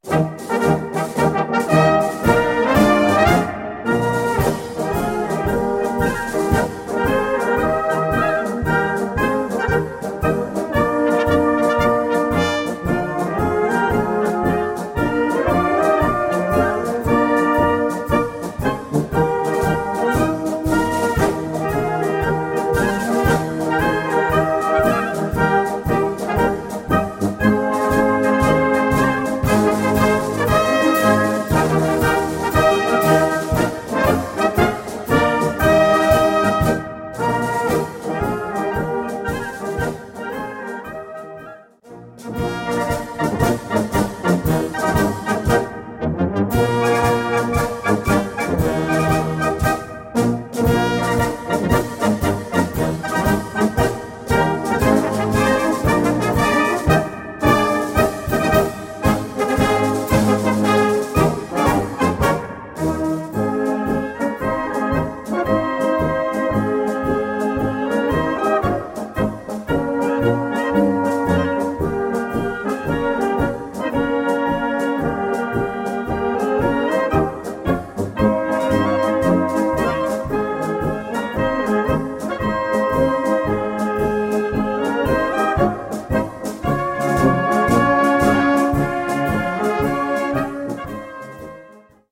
Blasorchester
Marschbuchformat
Diesen melodiösen und leicht spielbaren Marsch